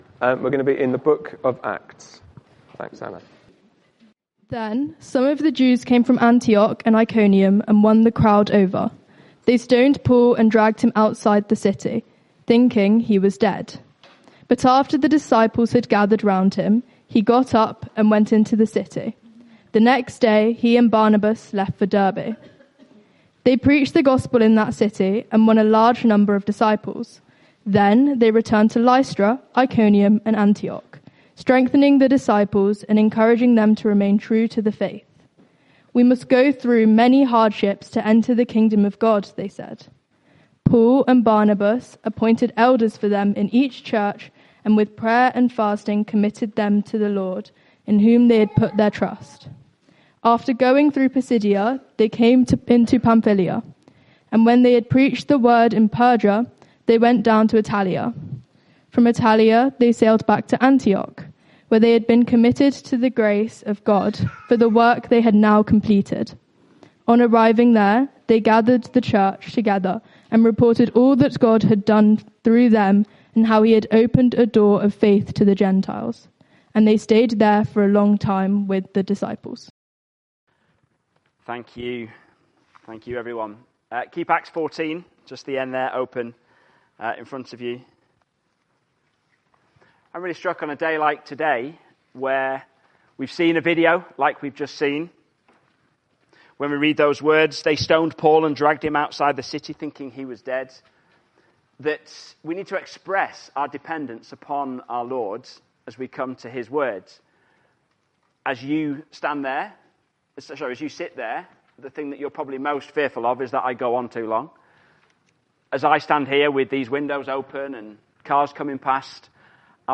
Sunday-Service-_-2nd-Nov-2025.mp3